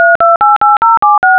DTMF Signals
When you press a button you generate a tone that is the sum of the column frequency and the row frequency.
This is called the DTMF (Dual-Tone Multiple-Frequency) signalling scheme.
touchtone.wav